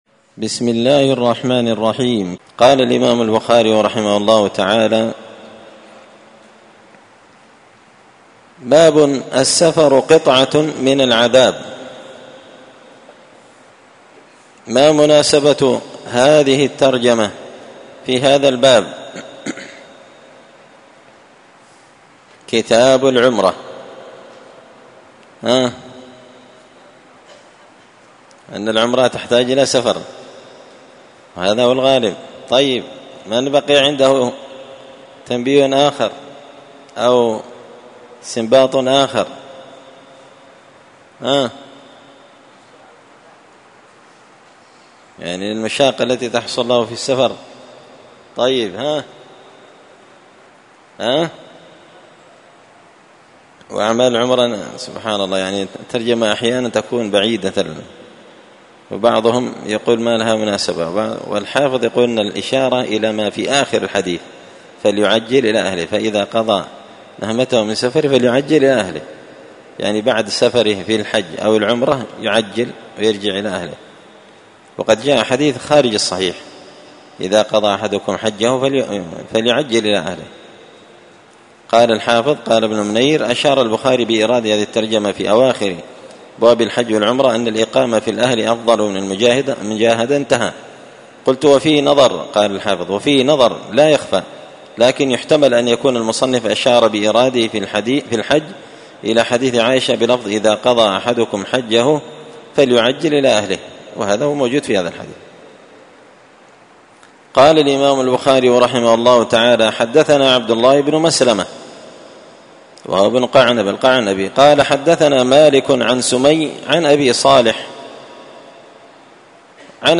كتاب العمرة من شرح صحيح البخاري- الدرس 17 باب السفر قطعة من العذاب